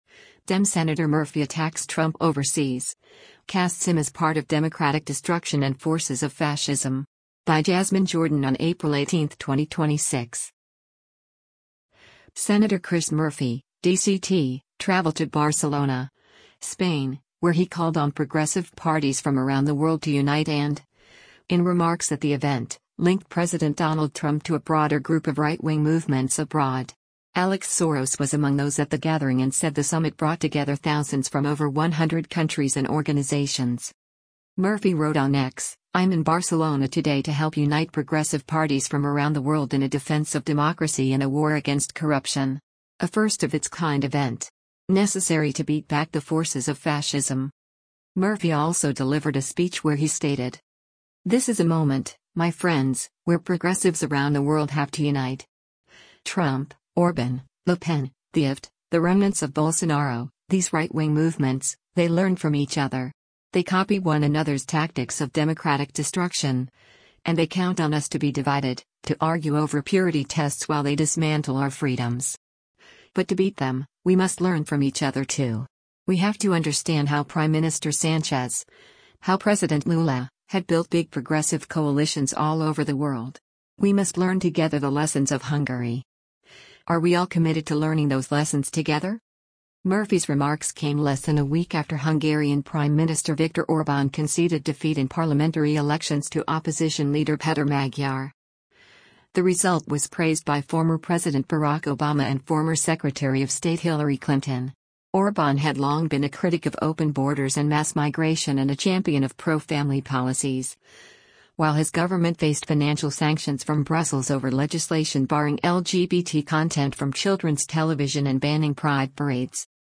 Murphy also delivered a speech where he stated: